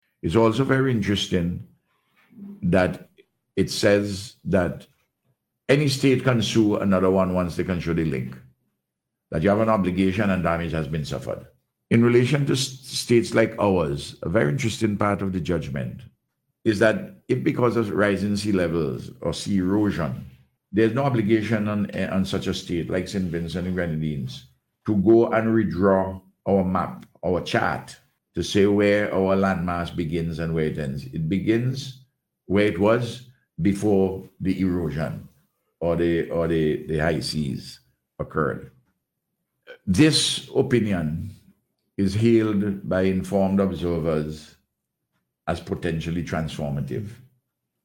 Prime Minister Gonsalves said on Radio yesterday that the ruling marks a significant victory for small states like St Vincent and the Grenadines.